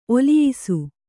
♪ oliyisu